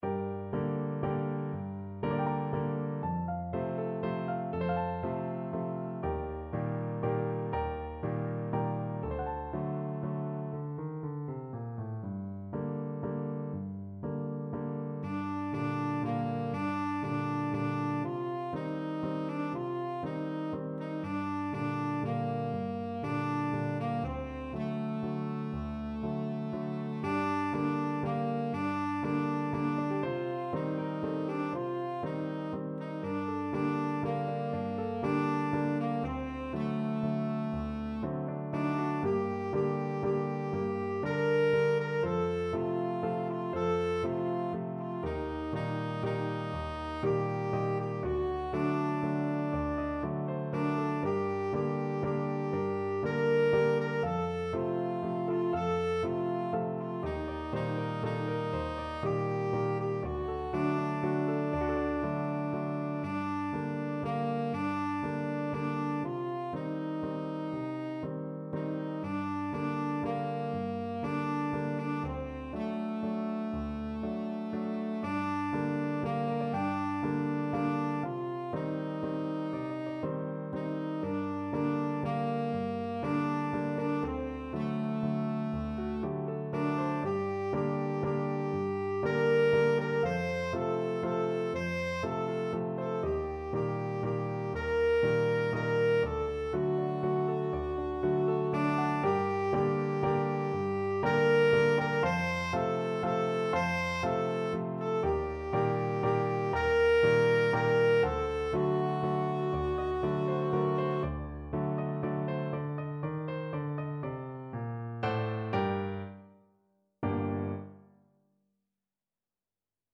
Tenor Saxophone
3/4 (View more 3/4 Music)
G minor (Sounding Pitch) A minor (Tenor Saxophone in Bb) (View more G minor Music for Tenor Saxophone )
Slow Waltz .=40